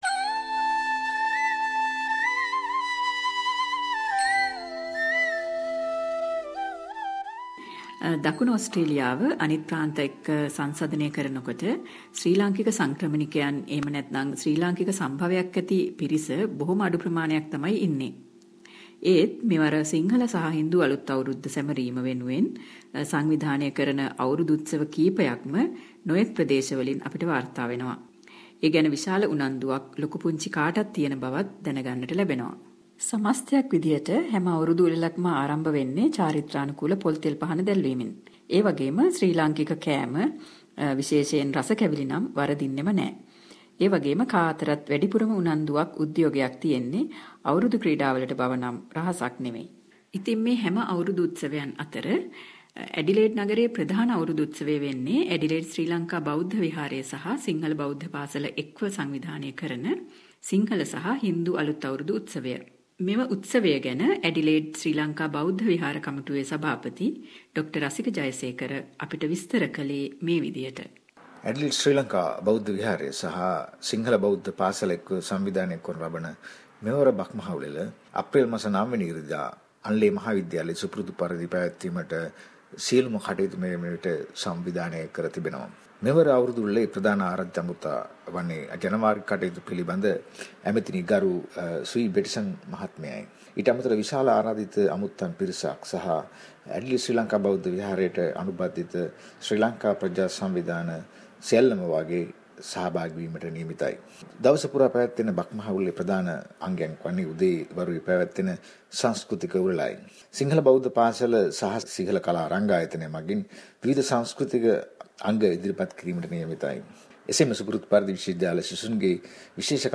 reporter to the SBS Sinhalese program from South Australia reports....